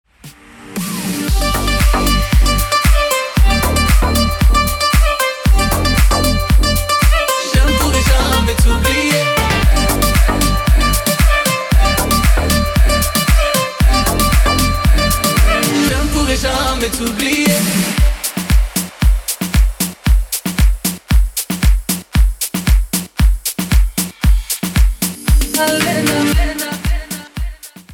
Minőség: 320 kbps 44.1 kHz Stereo